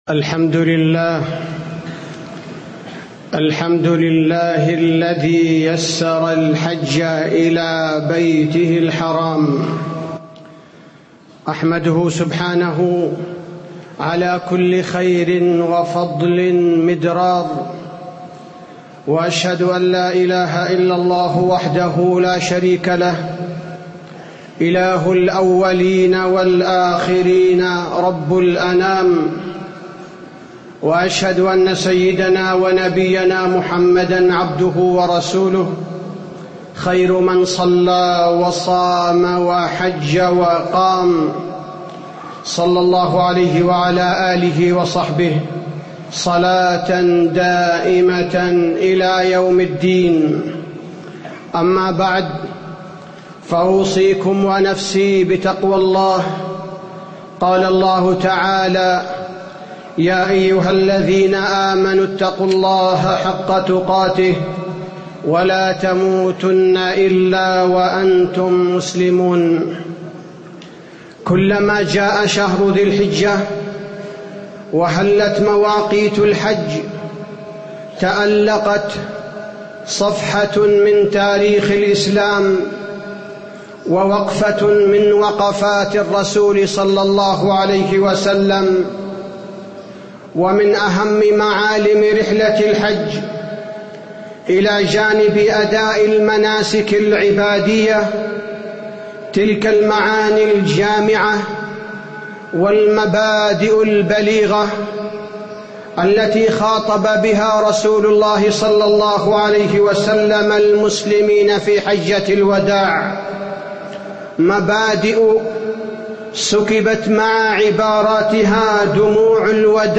تاريخ النشر ١ ذو الحجة ١٤٣٧ هـ المكان: المسجد النبوي الشيخ: فضيلة الشيخ عبدالباري الثبيتي فضيلة الشيخ عبدالباري الثبيتي دروس وعبر من حجة الوداع